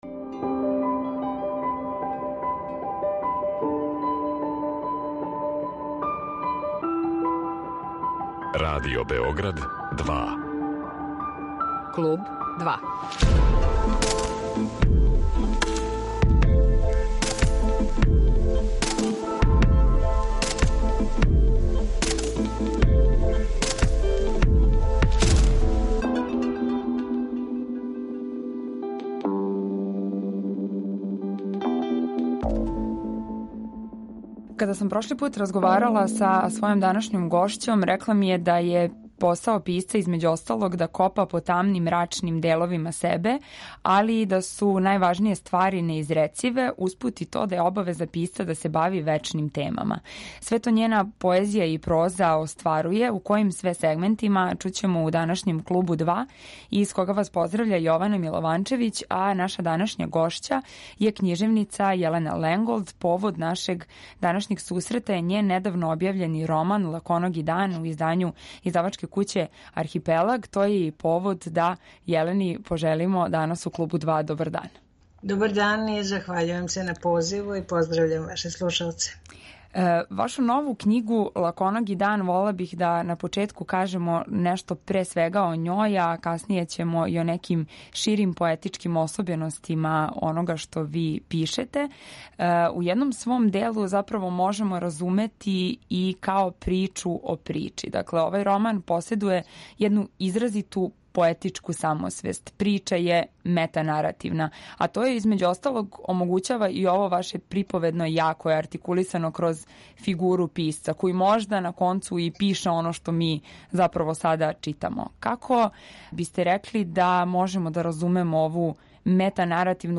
Гошћа данашњег Клуба 2 је књижевница Јелена Ленголд.